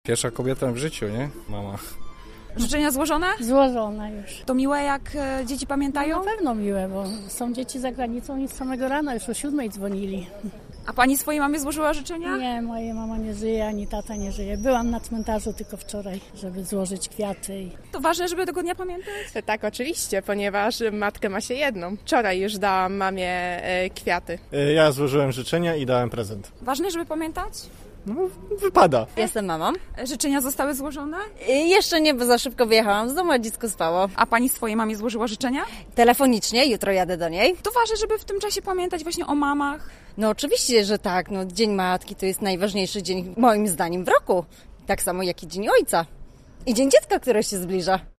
Z mikrofonem Radia Zielona Góra zajrzeliśmy na starówkę, by zapytać mieszkańców czy pamiętają o Dniu Matki: